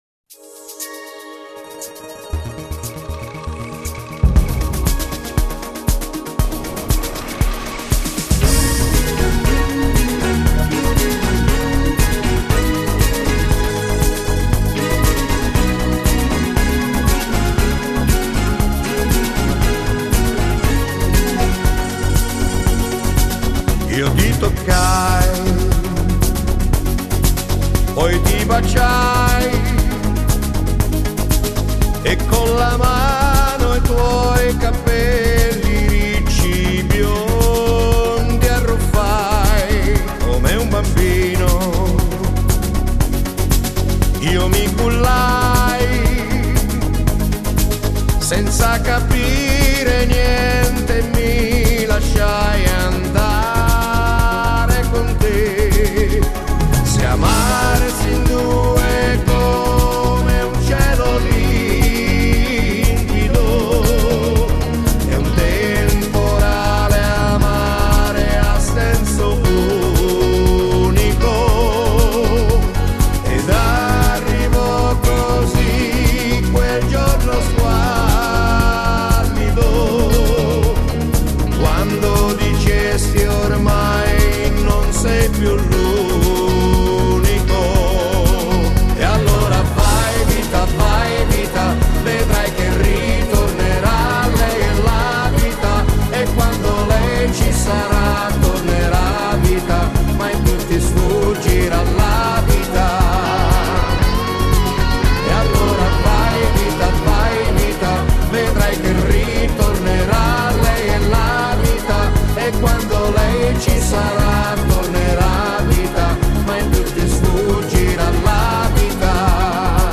Genere: Allegro